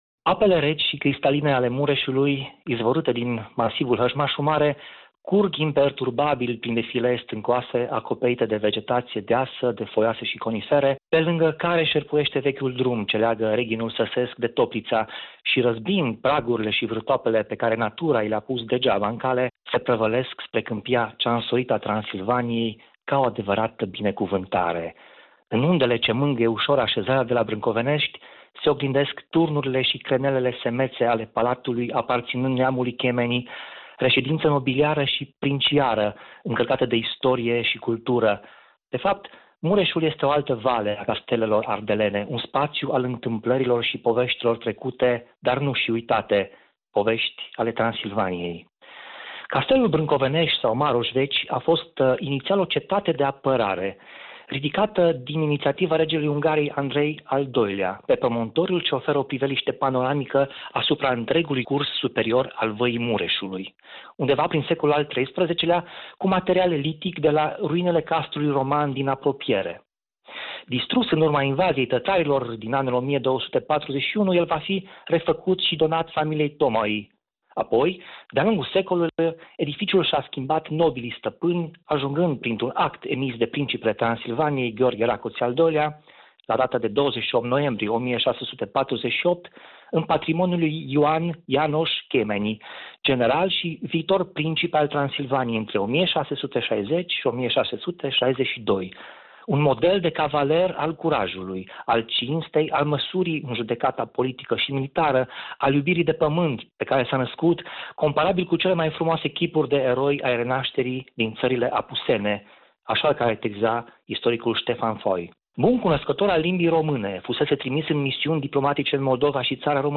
Istorie, literatură și o frumoasă poveste de dragoste la Brâncovenești spusă de istoricul